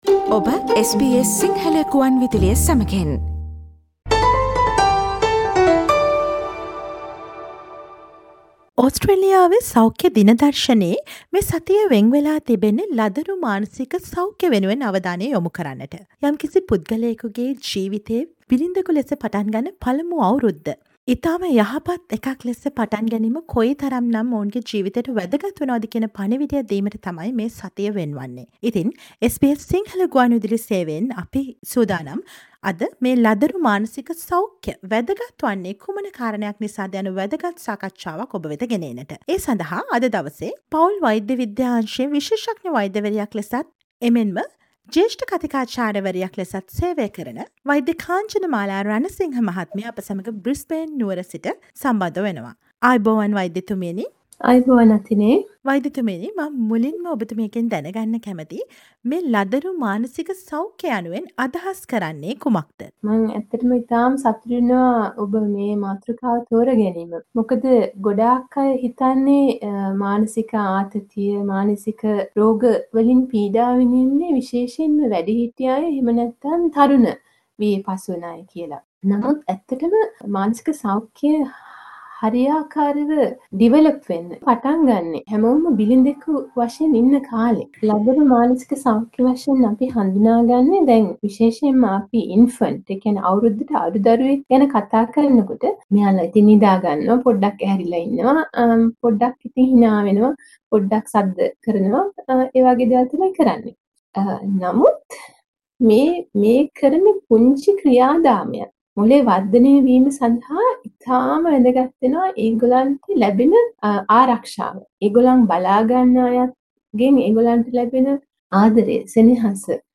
SBS සිංහල ගුවන් විදුලිය සිදු කළ සාකච්ඡාව